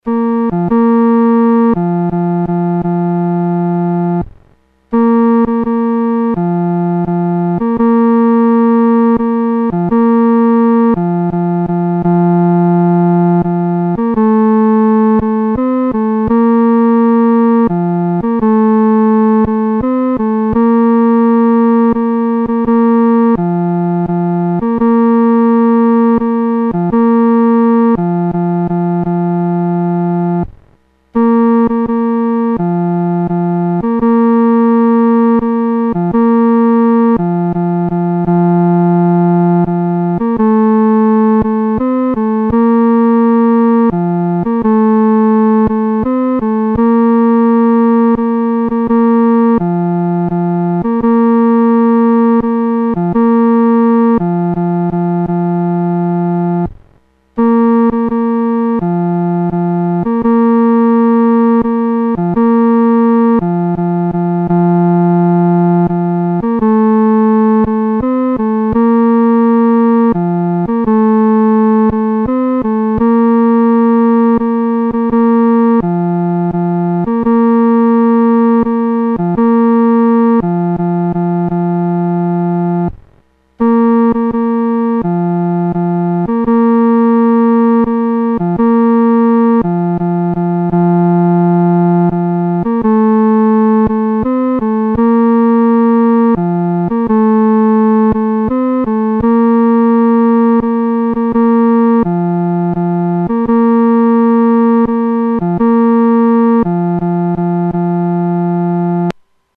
独奏（第三声）
万古磐石-独奏（第三声）.mp3